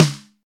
snare36.ogg